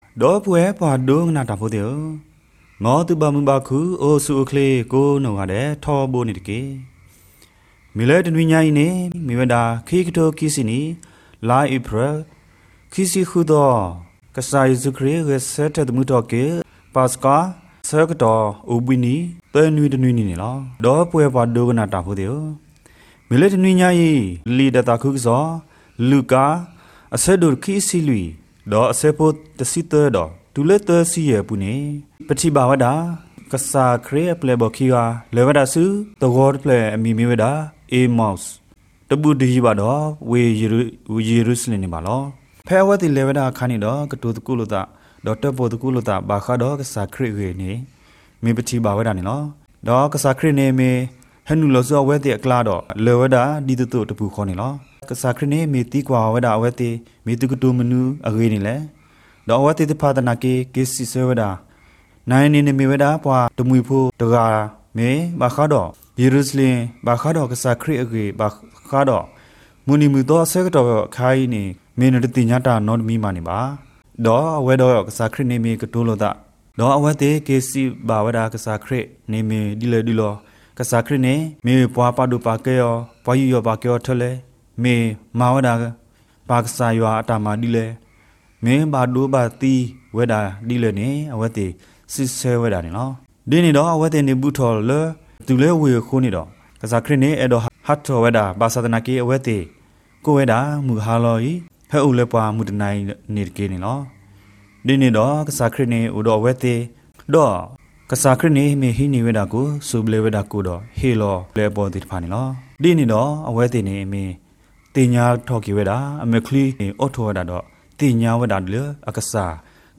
homily_rd_sun_easter.mp3